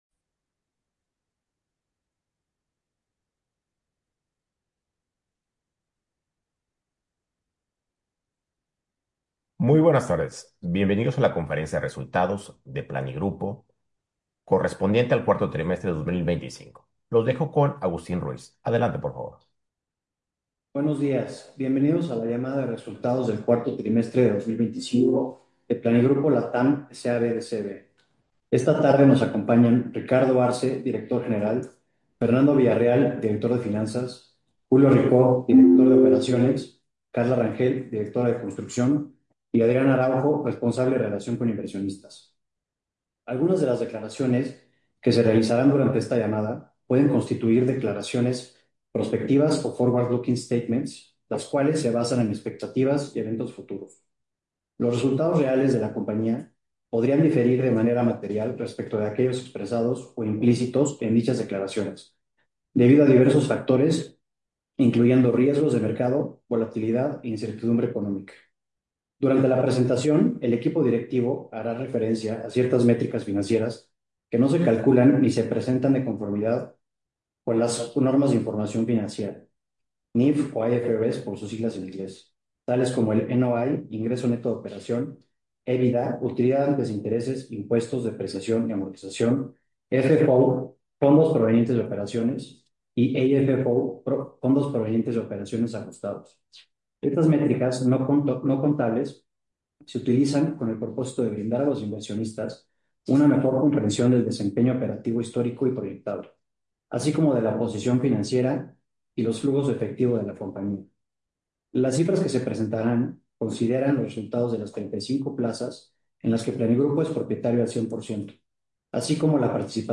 Audio de la Llamada de Resultados del 4to Trimestre 2025